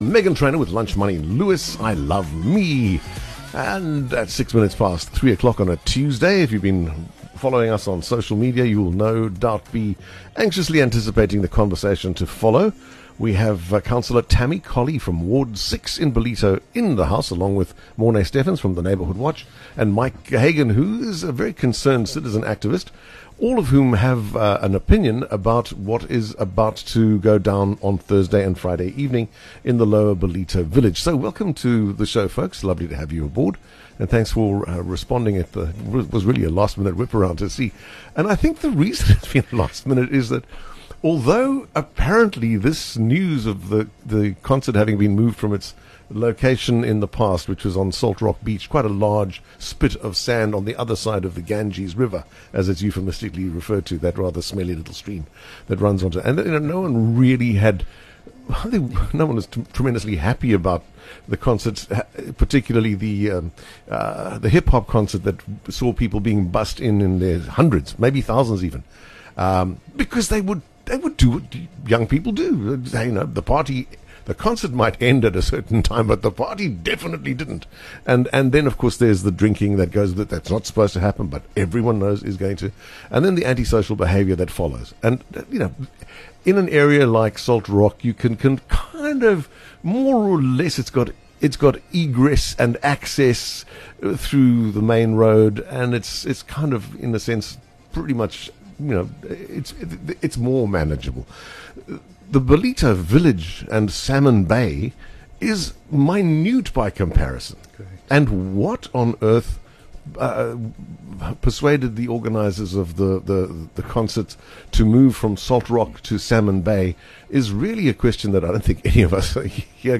It's a hot topic - catch the discussion live or later on The Afternoon Show podcast.